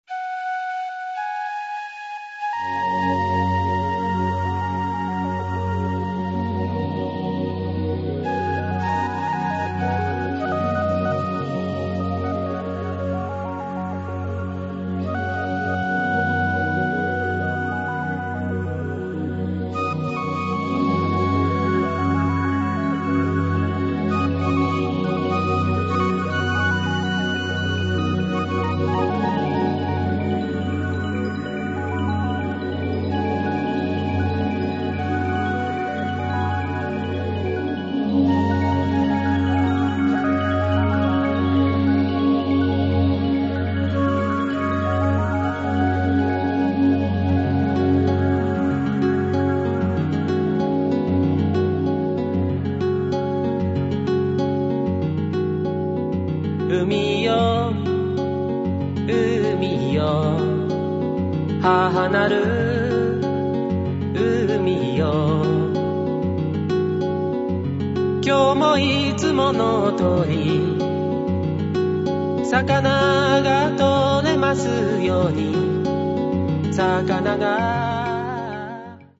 • ファイルサイズ軽減のため、音質は劣化しています。
歌・シンセサイザー演奏